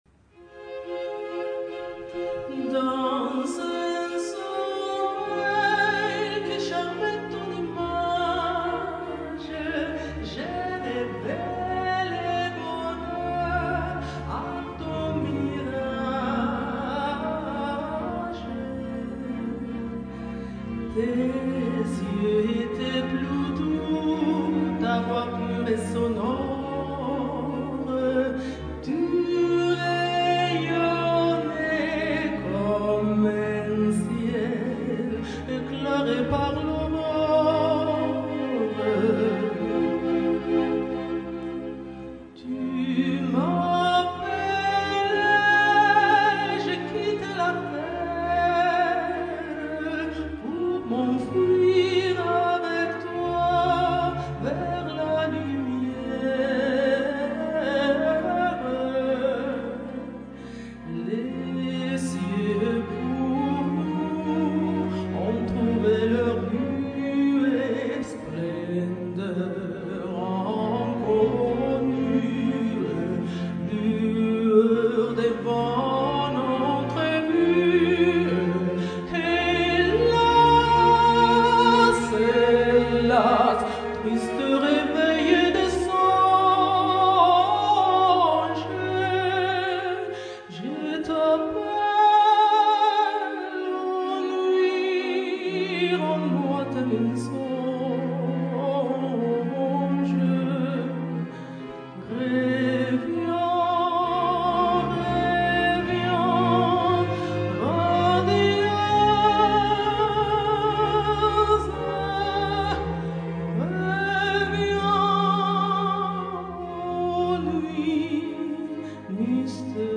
voce solista
pianoforte
flauto
violini
viole
violoncelli
contrabbasso, fisarmonica
GenereMusica Classica / Cameristica